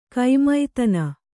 ♪ kaimaitana